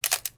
Camera Click 35mm Manual.wav